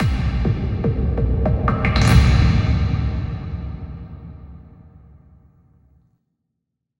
Processed Hits 17.wav